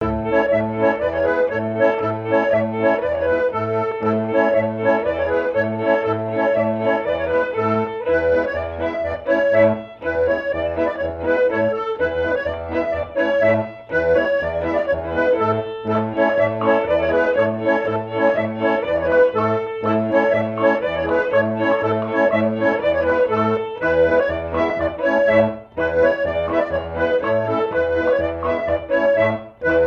danse : branle : courante, maraîchine
Pièce musicale éditée